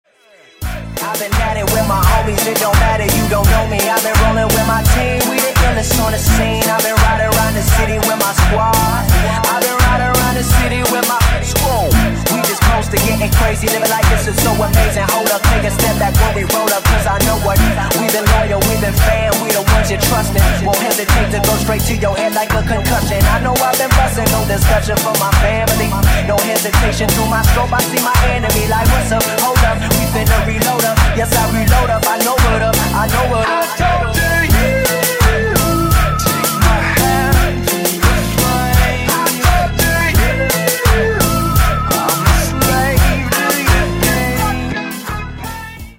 • Качество: 128, Stereo
Хип-хоп
Moombahton